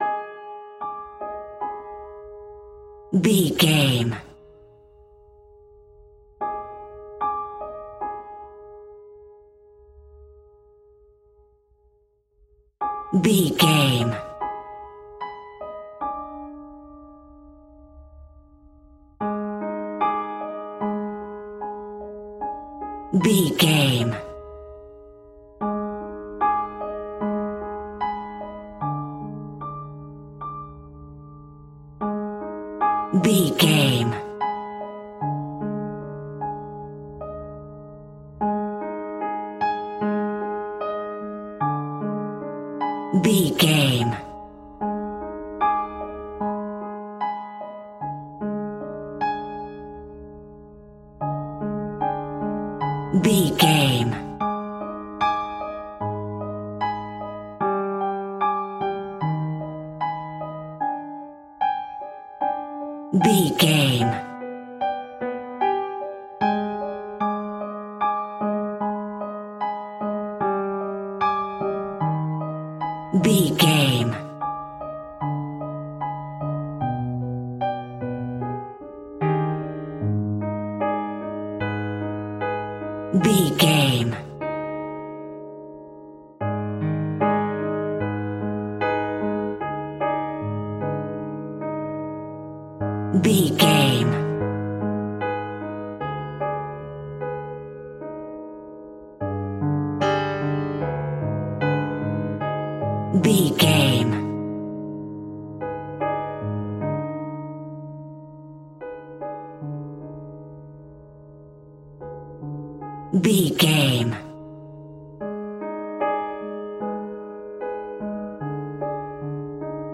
In-crescendo
Thriller
Aeolian/Minor
tension
ominous
dark
haunting
eerie
Acoustic Piano